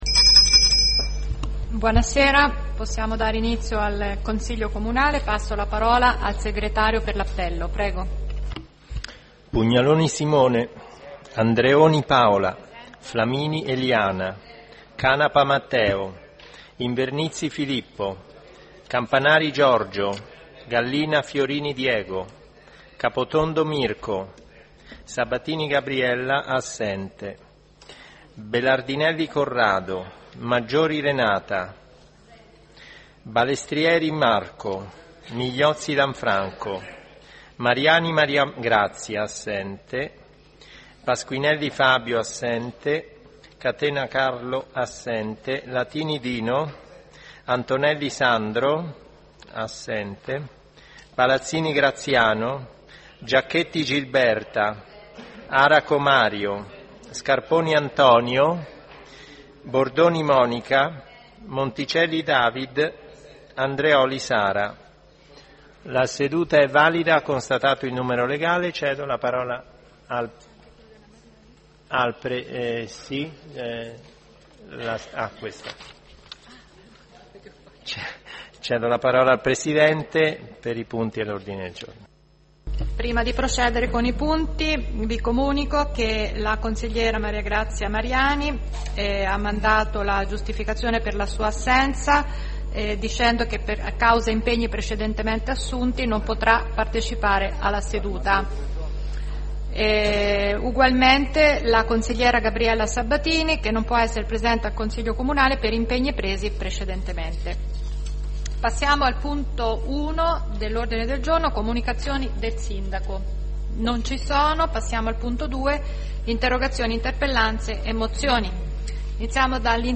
REGISTRAZIONE DELLA SEDUTA DI CONSIGLIO COMUNALE DEL 14 MARZO 2019